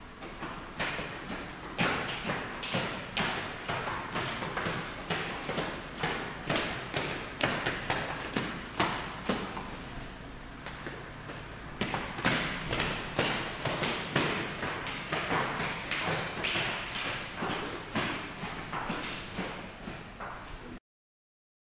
Tag: 脚步 碎石 台阶 台阶 走路的脚步